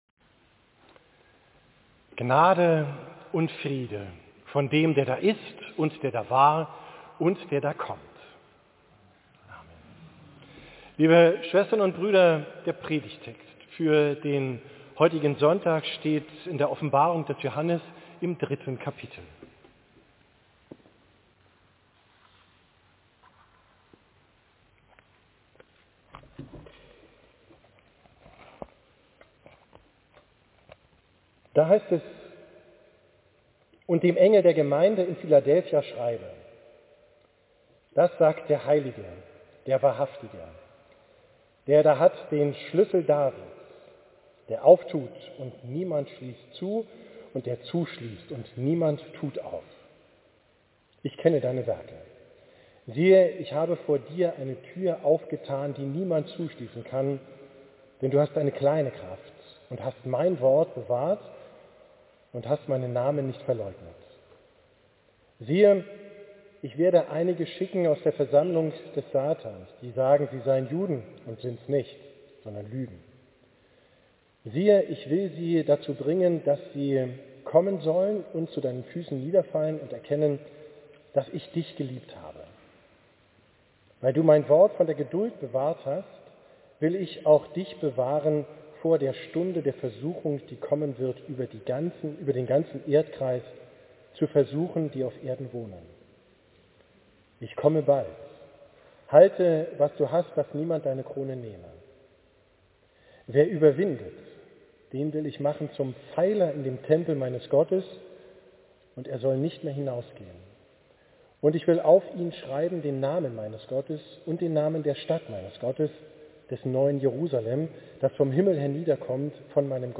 Predigt vom 2.